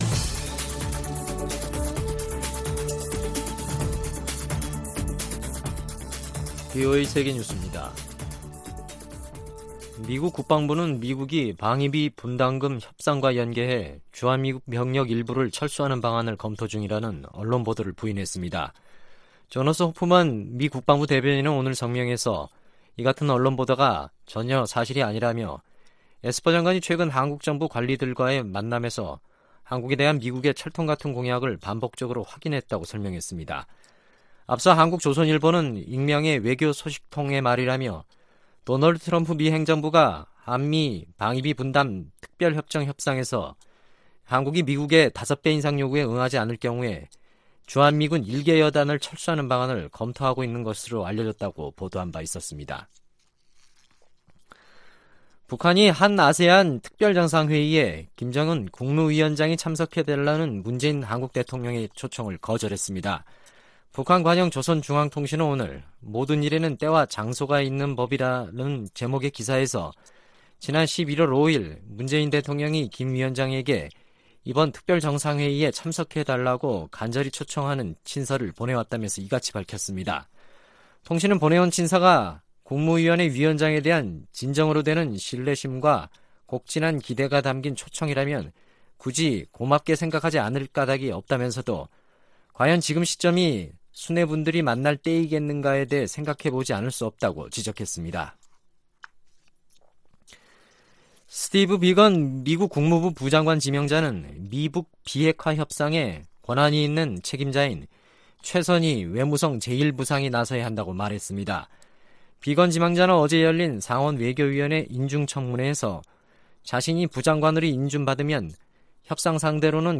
VOA 한국어 간판 뉴스 프로그램 '뉴스 투데이', 2019년 11월 21일 2부 방송입니다.